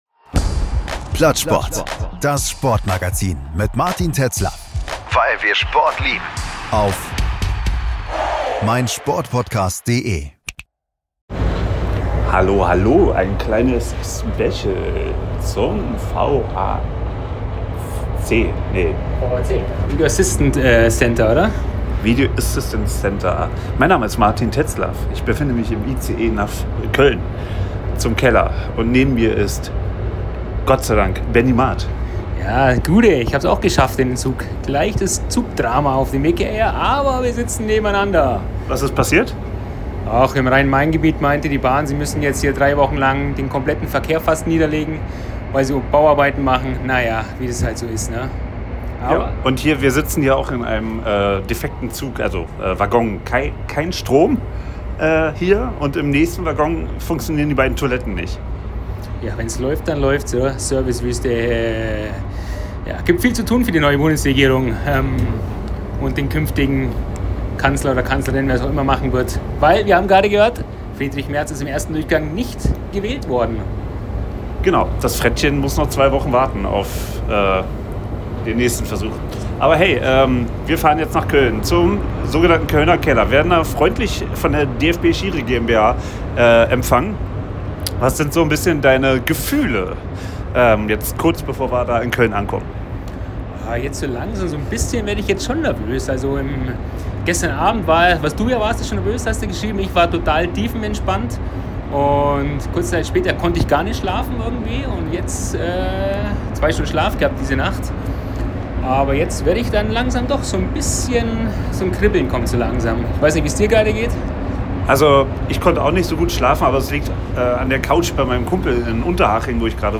Wir haben im Zug, quasi vor dem Kölner Dom und auf dem Umstieg zurück nach Hause am Airport in Frankfurt unsere Erwartungen gebündelt und sprechen im Nachgang sehr begeistert und dennoch reflektiert über unsere Eindrücke.